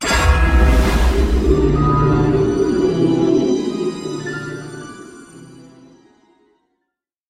Подборка создана для проектов, где нужна мрачная или тревожная атмосфера.
Звук таинственного яда в фейри тейл